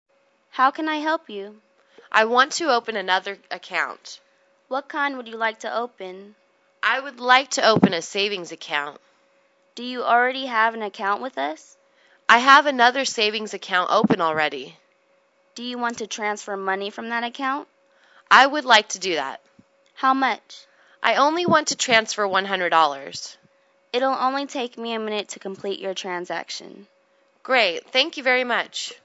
银行英语对话-Opening Another Account(2) 听力文件下载—在线英语听力室